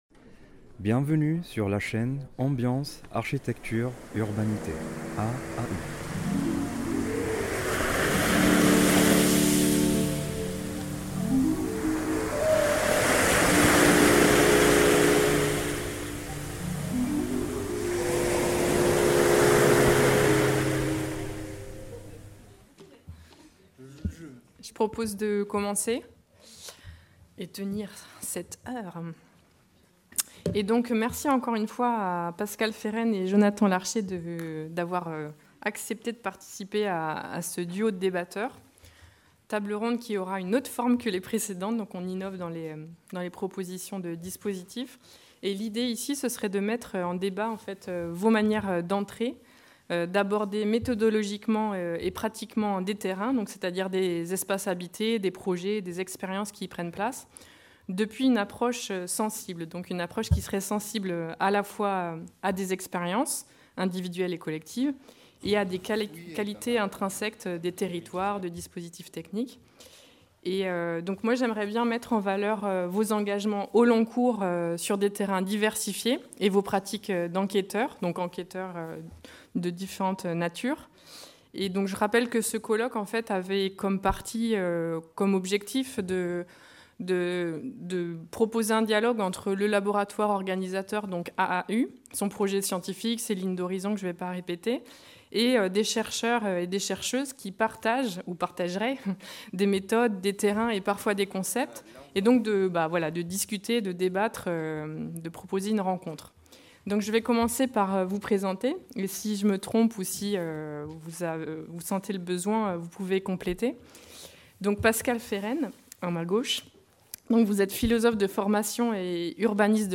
Troisième duo du Colloque international Expériences sensibles, fabrique et critique des territoires en mutation